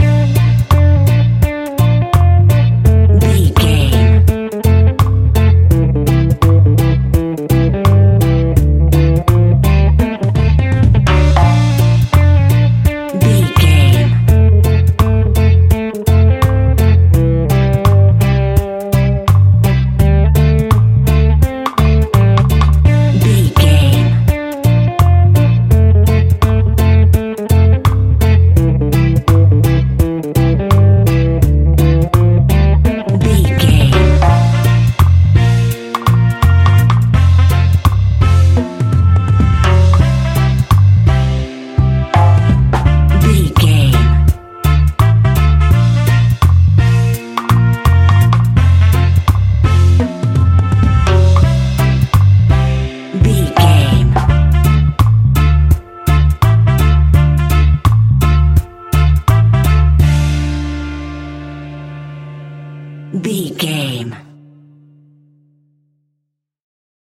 Classic reggae music with that skank bounce reggae feeling.
Aeolian/Minor
D
reggae instrumentals
laid back
chilled
off beat
drums
skank guitar
hammond organ
percussion
horns